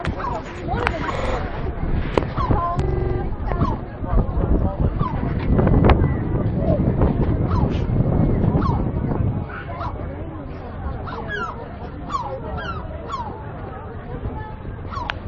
Am Sonnabend waren wir in Whitby. Das ist ein kleiner Küstenort.
Hier als kleiner Eindruck ein paar Möwen
moewen_whitby.mp3